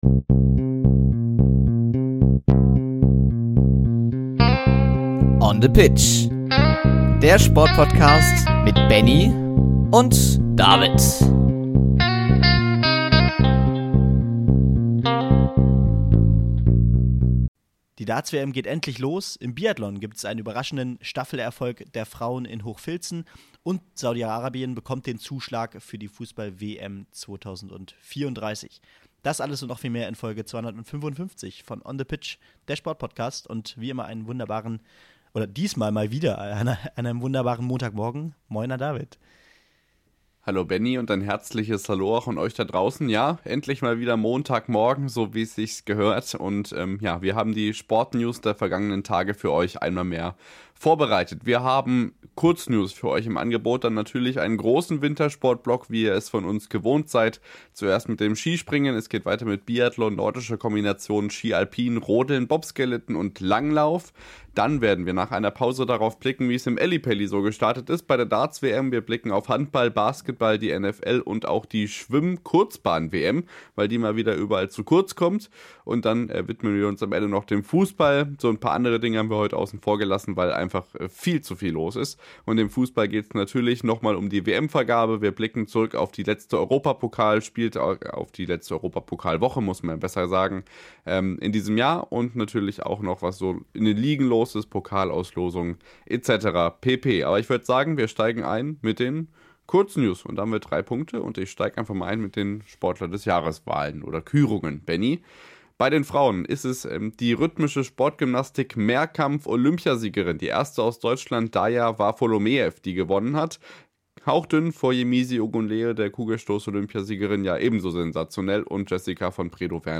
Ohio University Bobcats 1:26:20 Play Pause 10d ago 1:26:20 Play Pause Riproduci in seguito Riproduci in seguito Liste Like Like aggiunto 1:26:20 Eine neue Footballschland Podcast Folge - ein neuer Gast.